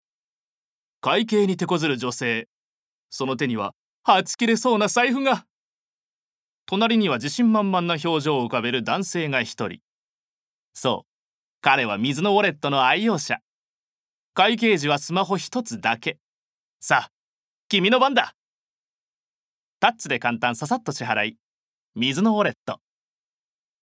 ボイスサンプル
ナレーション２